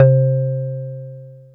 JUP.8 C3   3.wav